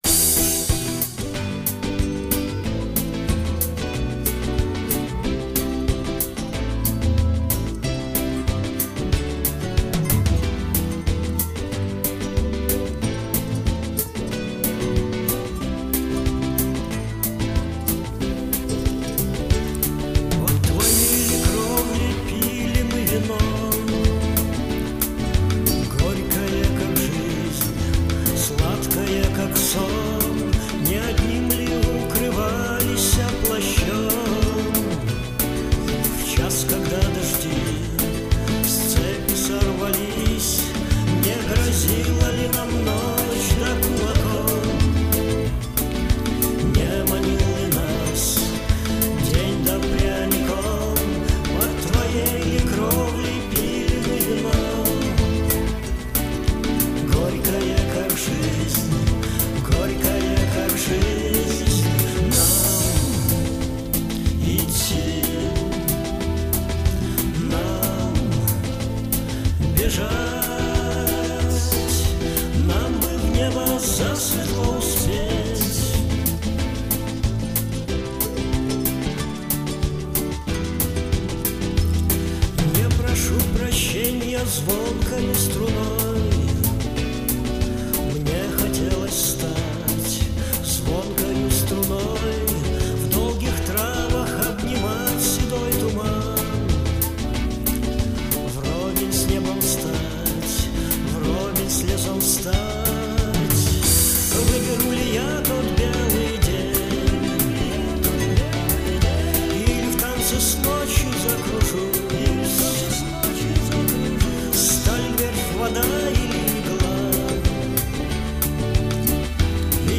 Арт-рок и авангард.